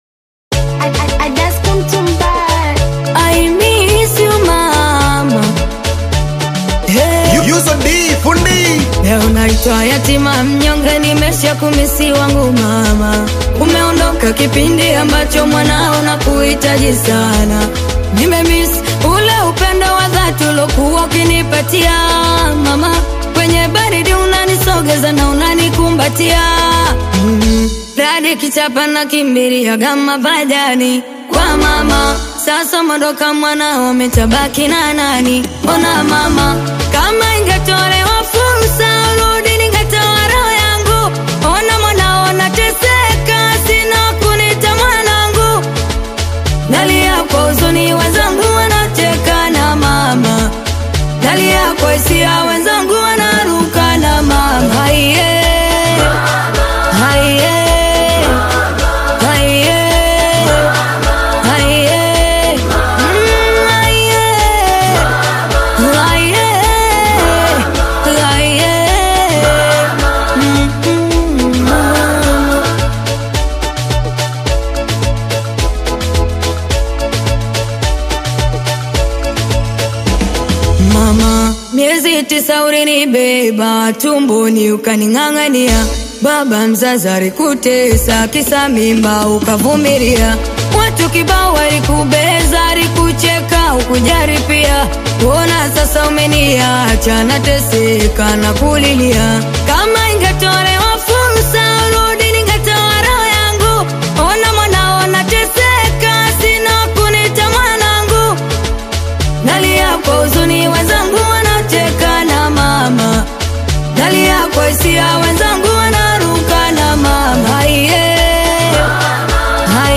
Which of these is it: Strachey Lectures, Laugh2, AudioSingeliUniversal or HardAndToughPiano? AudioSingeliUniversal